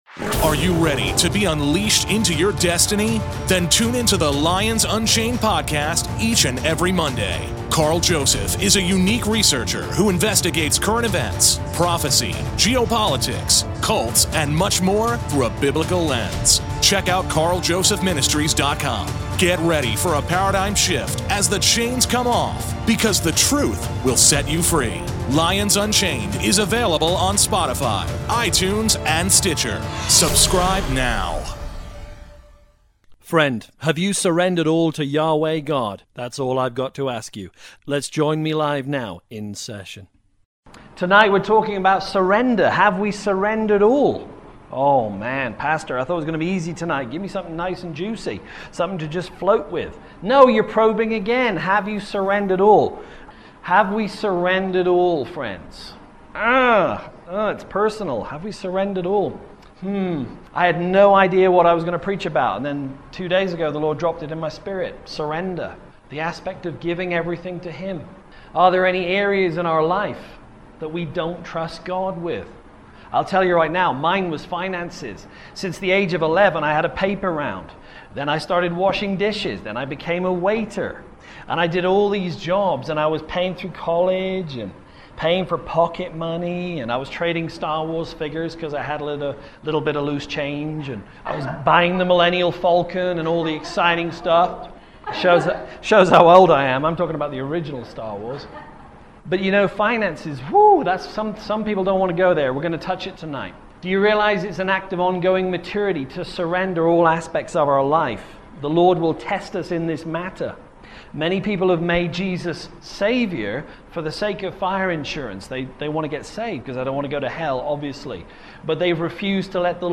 Part 1 (LIVE)